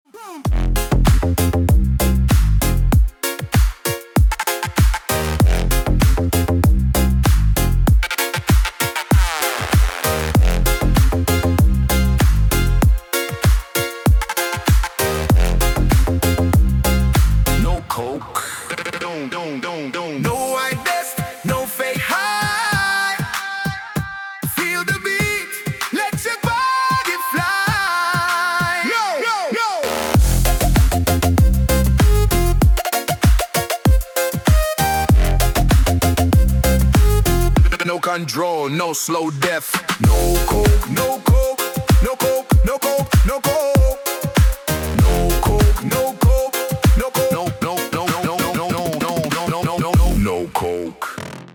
рингтон в ретро стиле 90-х